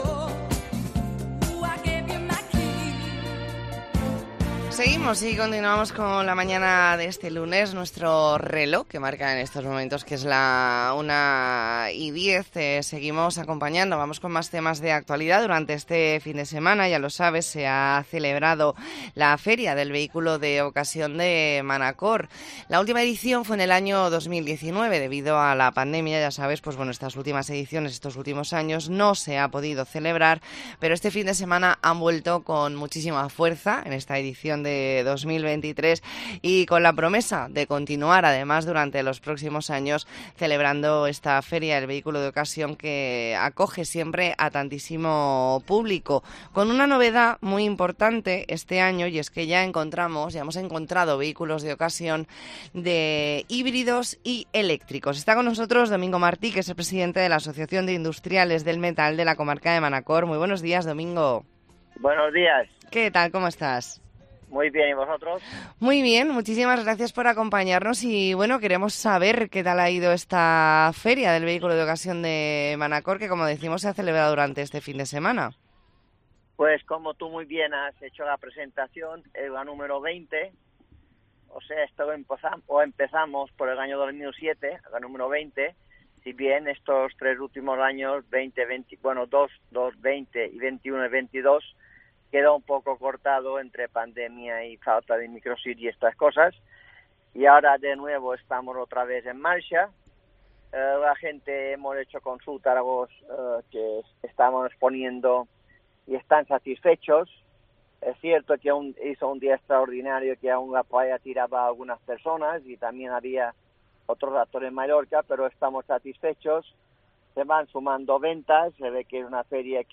Entrevista en La...